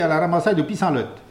Patois